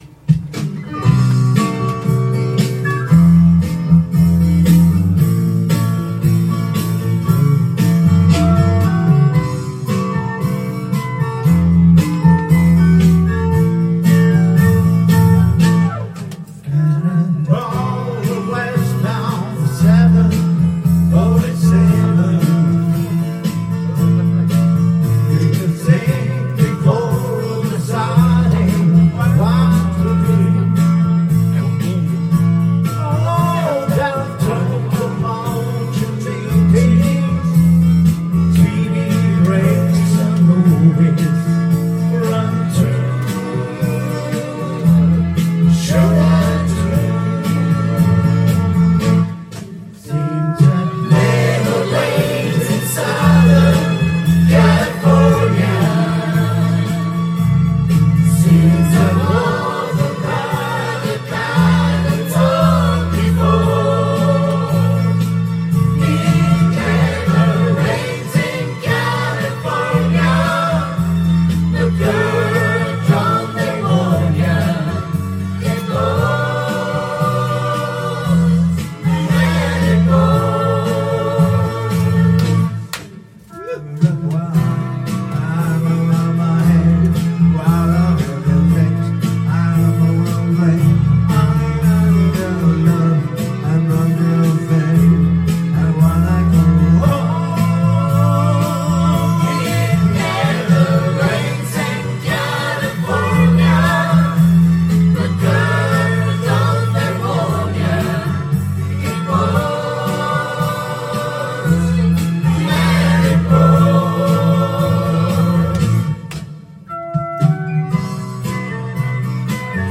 Duet & Chorus Night Vol. 18 TURN TABLE
10 【divaコーラスエントリー】